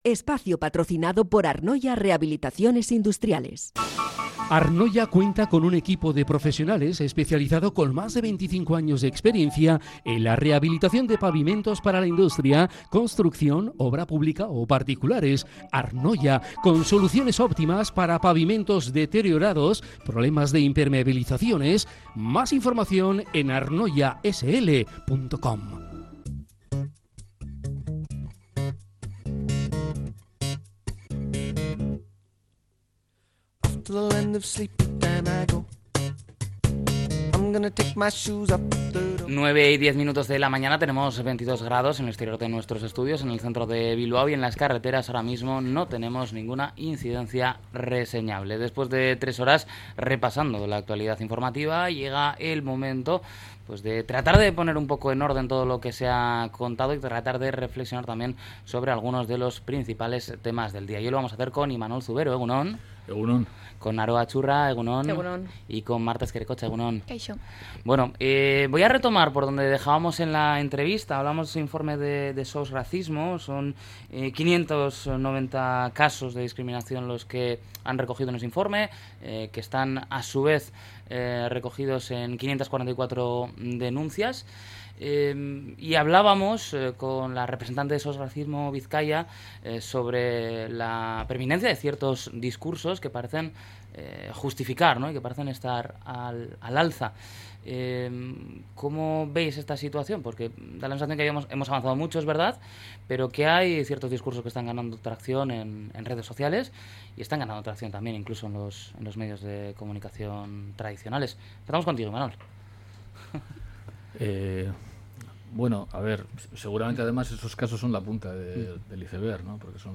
La Tertulia de EgunOn Bizkaia 03-07-25